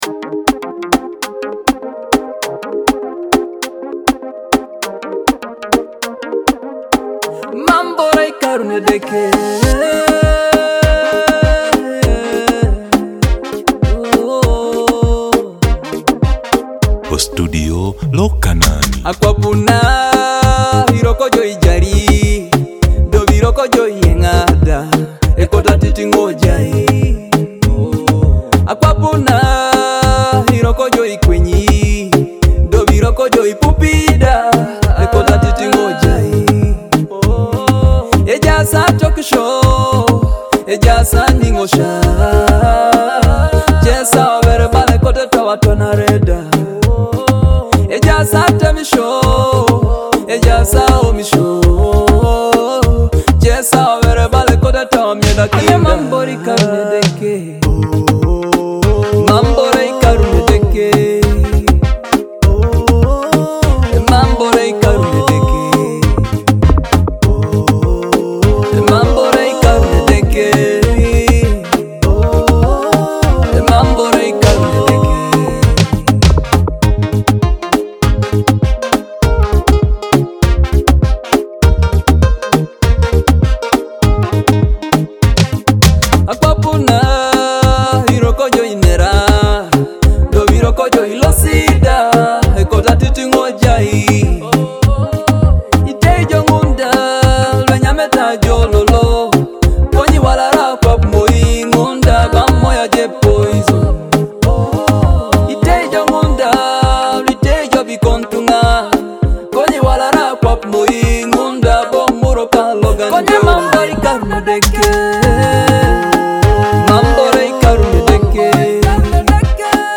soul-stirring sound of gospel music